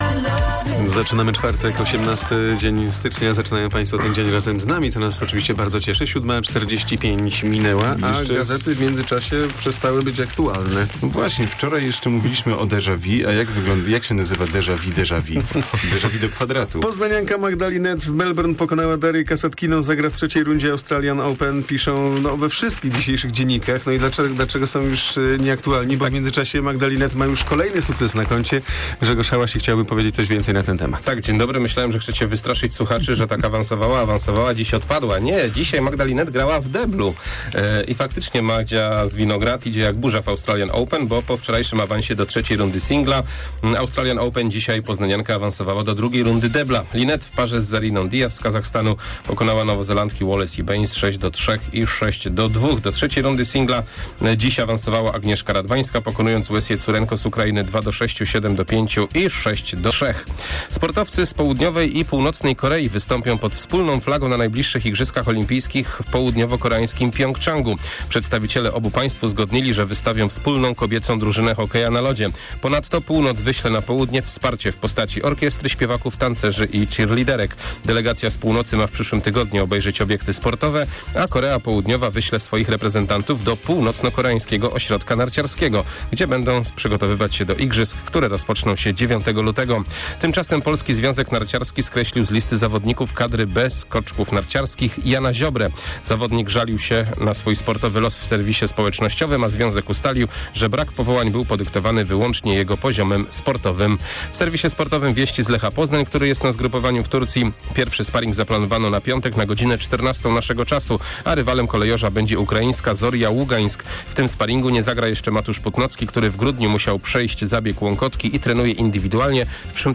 18.01 serwis sportowy godz. 7:45